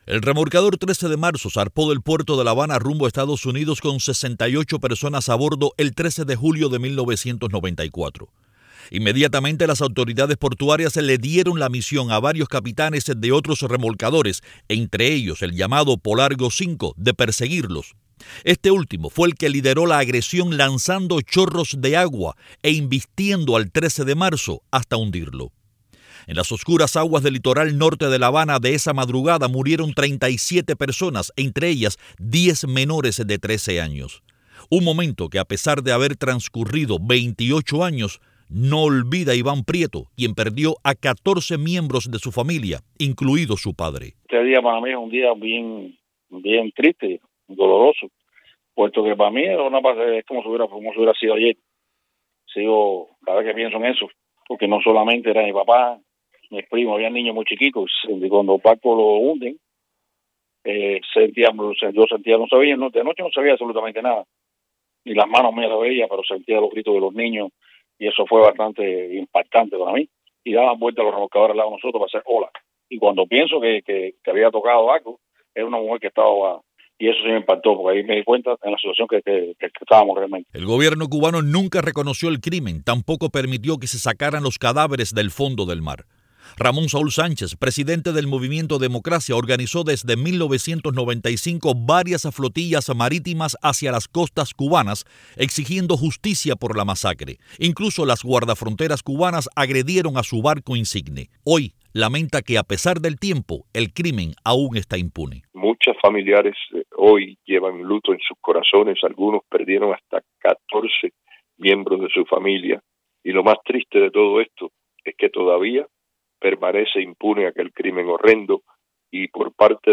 Familiares de víctimas del remolcador 13 de Marzo hablan para Radio Martí